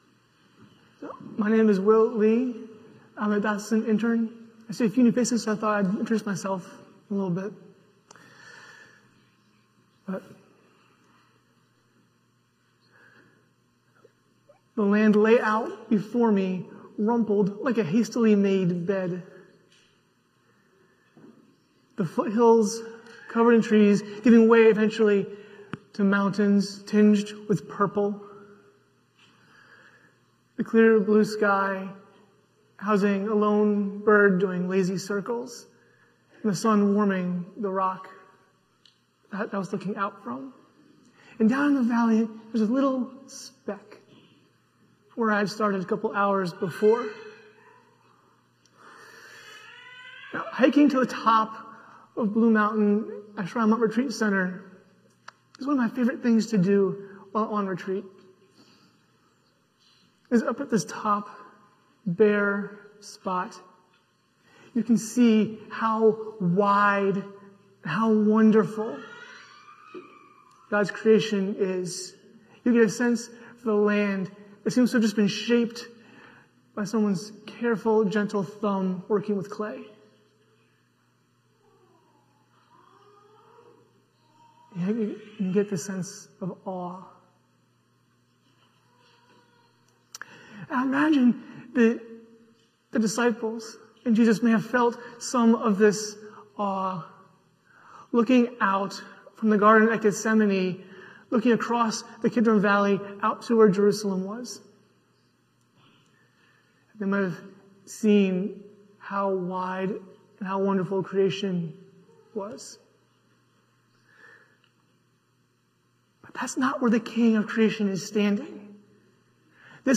St-Pauls-HEII-9a-Homily-24NOV24.mp3